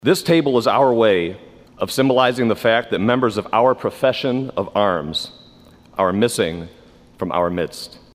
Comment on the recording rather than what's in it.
In the State Senate Thursday morning, the 22nd annual memorial day service was held.